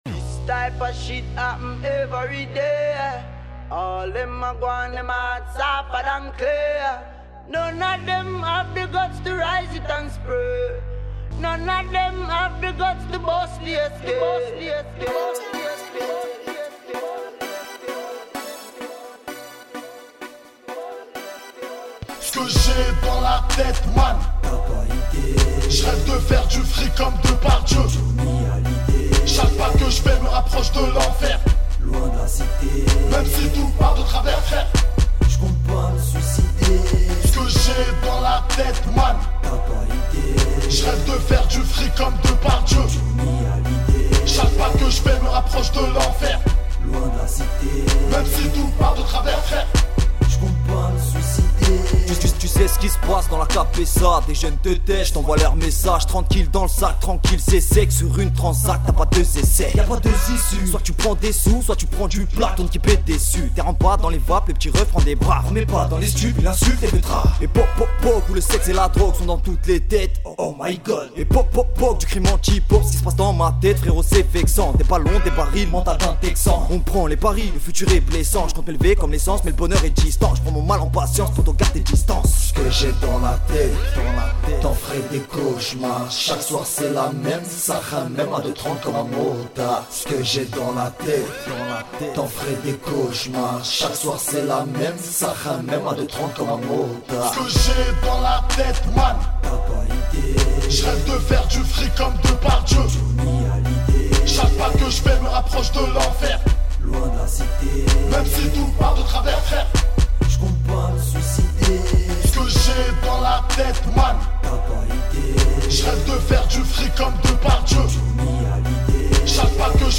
RAP INDEPENDANT